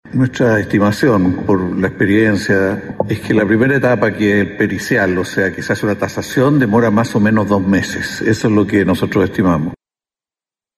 El proceso contempla una etapa pericial inicial que podría demorar cerca de dos meses, según explicó el ministro de Vivienda, Carlos Montes.